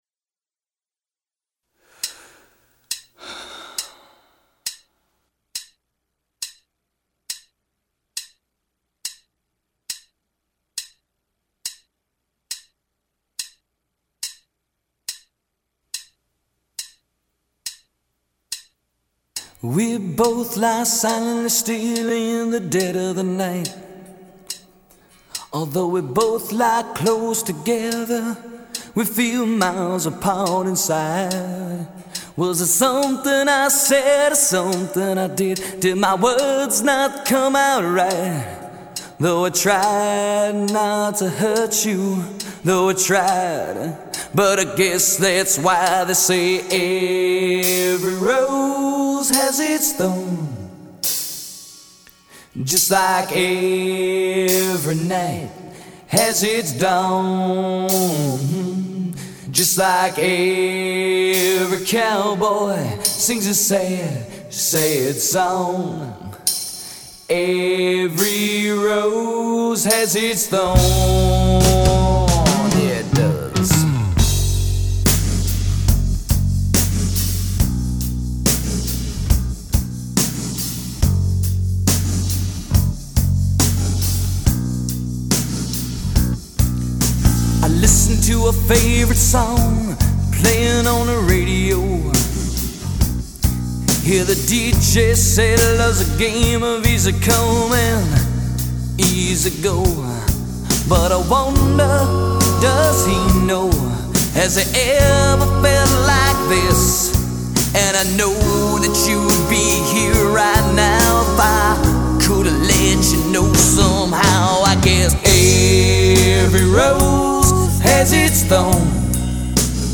Tempat Download Backing Track